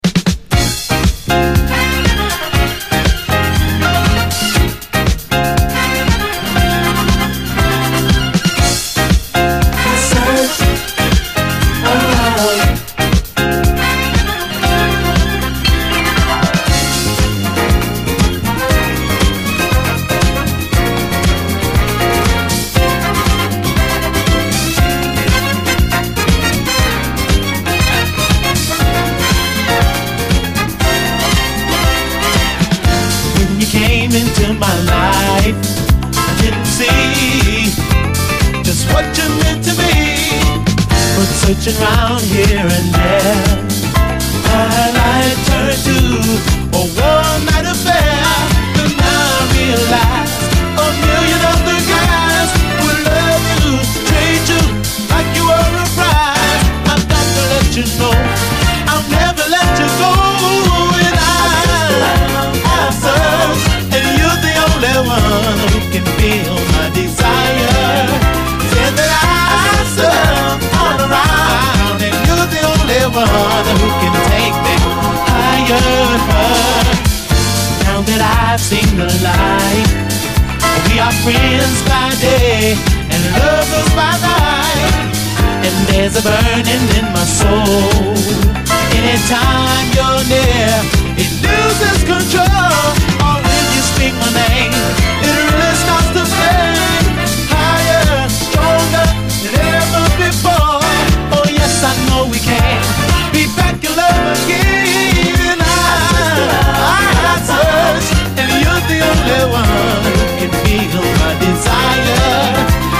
SOUL, 70's～ SOUL, DISCO
真夏のドライブで聴いたらそのまま昇天してしまいそうなくらいに疾走する、キラー・トロピカル・モダン・ソウル・ダンサー
底抜けの多幸感と南国トロピカル・フレイヴァーが突き抜けてる、最高トラック！